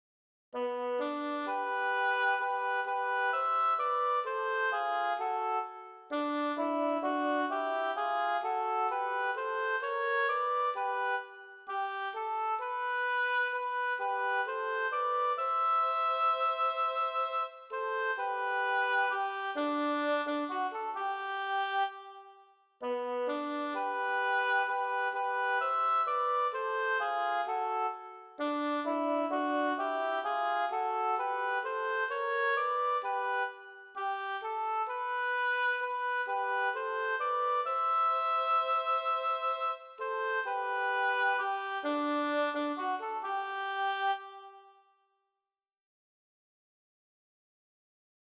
La m�lodie.